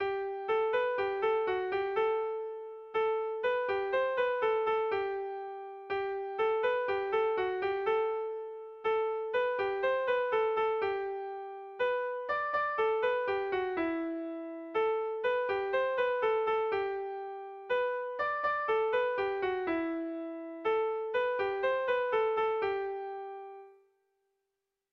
Erlijiozkoa
AAB